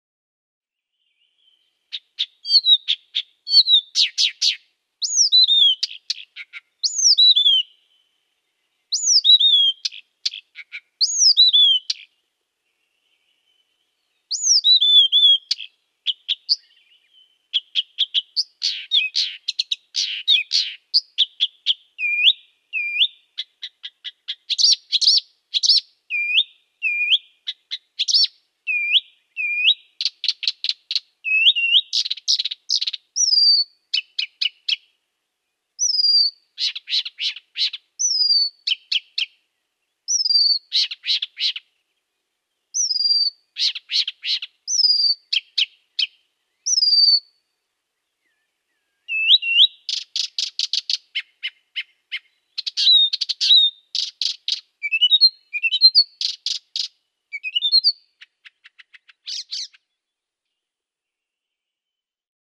Kesäyön ilmaiskonsertteja: Viitakerttunen improvisoi
Se on häkellyttävän taitava matkija ja mestarillinen improvisoija, lintumaailman jazzmuusikko. Viitakerttunen lainaa säveliä lukuisilta muilta lajeilta ja punoo niistä nauttivia musiikillisia kudelmia.
Esiintymispaikka on tyypillisesti tiheässä kasvustossa, esimerkiksi mesiangervotiheikössä tai seljapensaassa. Laulu on parhaimmillaan ilta- ja aamuyöstä ja voi jatkua jopa tunteja yhteen menoon.